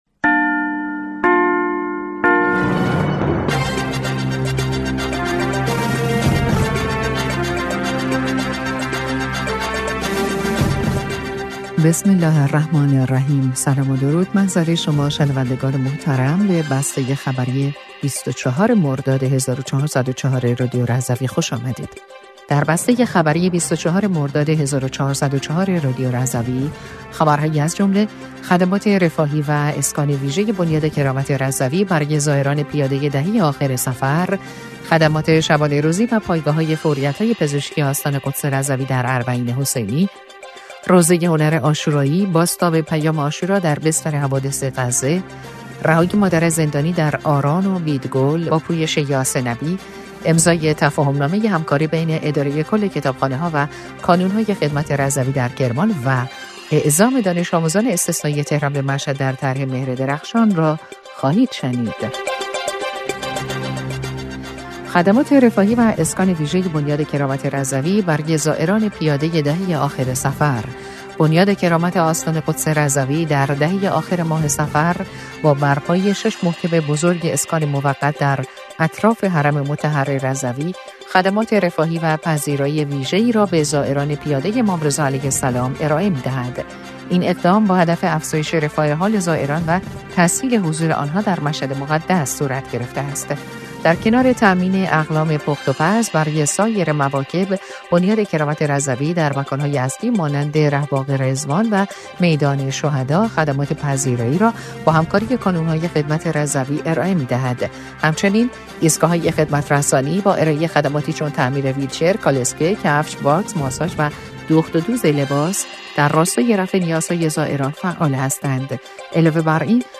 بسته خبری ۲۴ مردادماه ۱۴۰۴ رادیو رضوی/